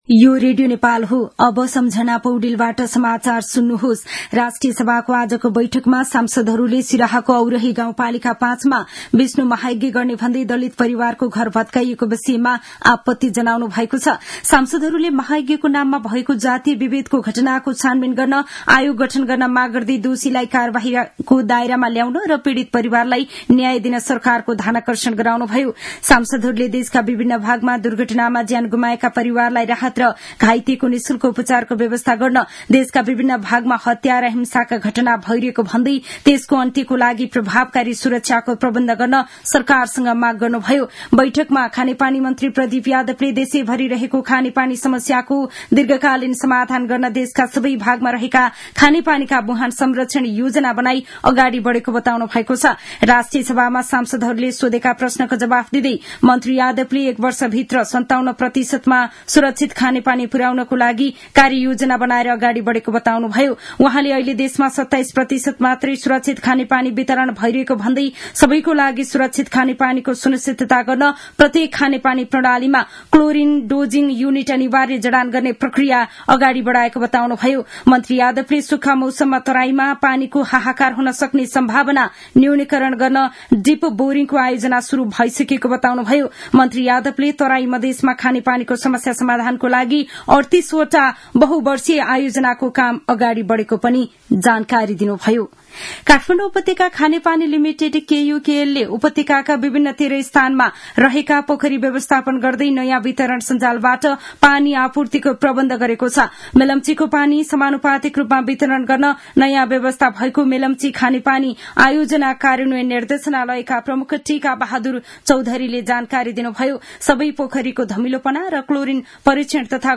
दिउँसो १ बजेको नेपाली समाचार : ८ चैत , २०८१
1-pm-news-1-6.mp3